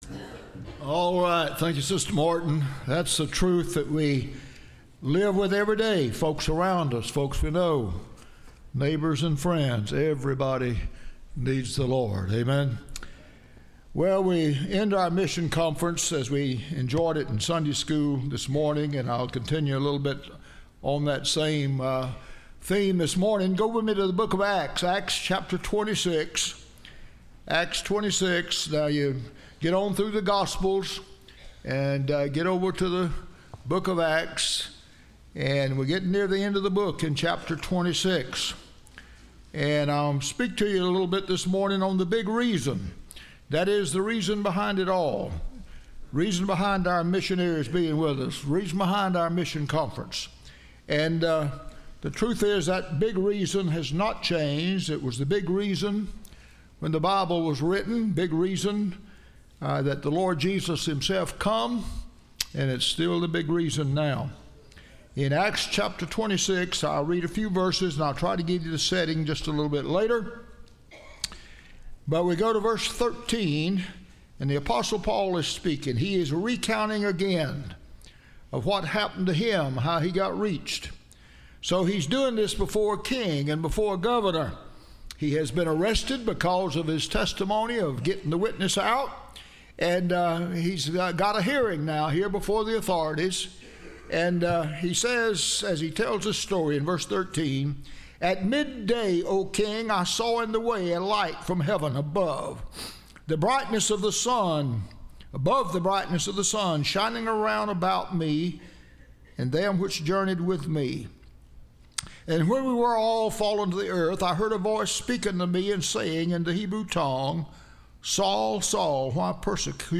Testimonies – Landmark Baptist Church
Service Type: Missions Conference